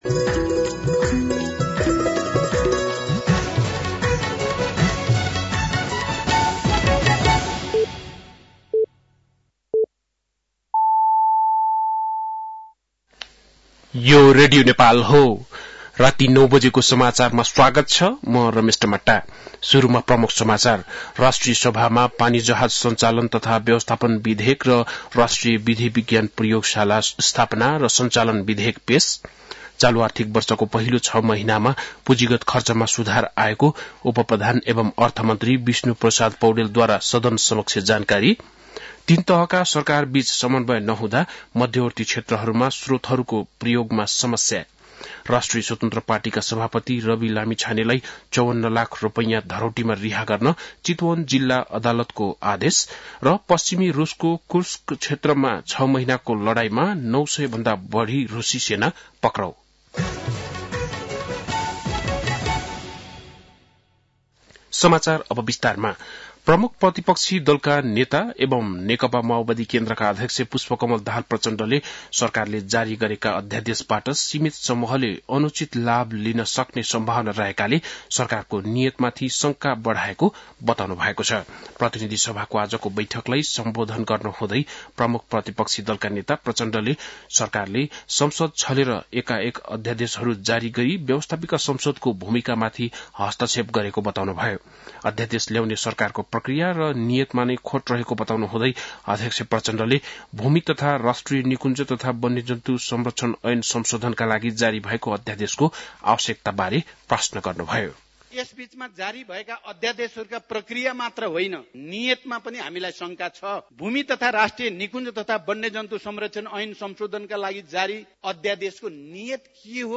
बेलुकी ९ बजेको नेपाली समाचार : २५ माघ , २०८१
9-PM-Nepali-NEWS-10-24.mp3